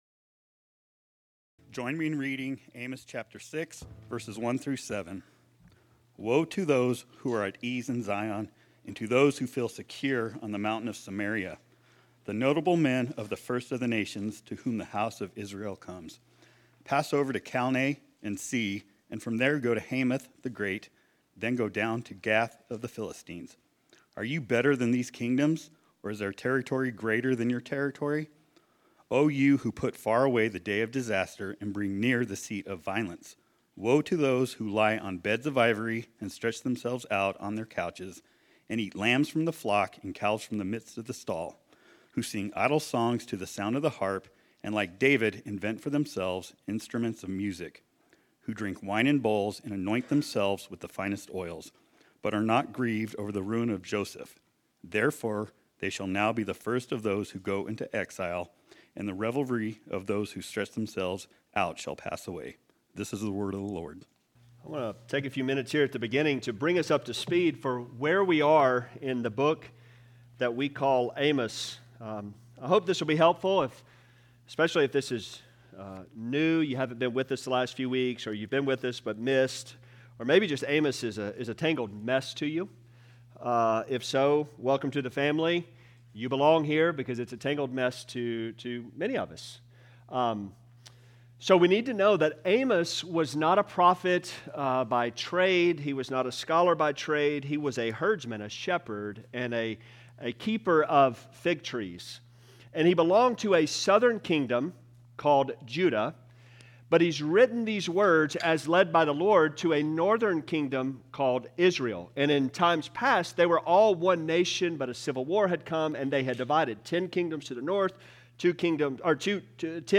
Unaware of Reality Sermon